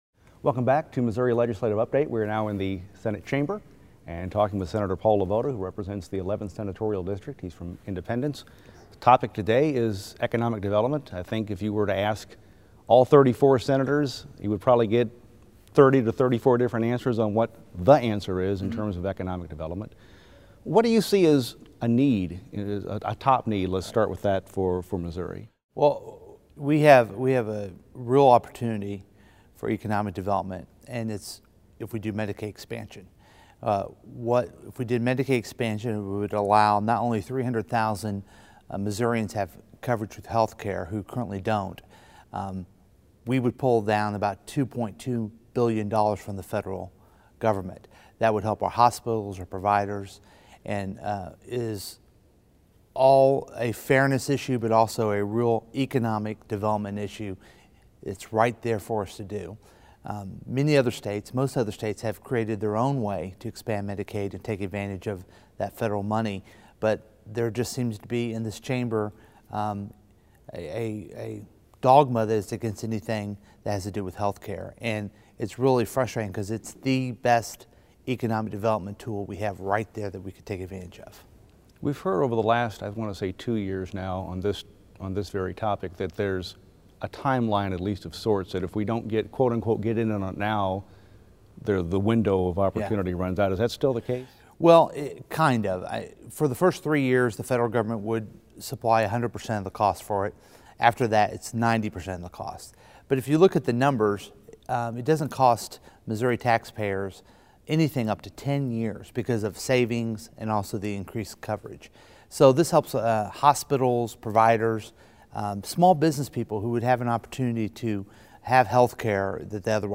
The audio and video below is a full-length interview with Sen. LeVota, taken from the February edition of Missouri Legislative Update.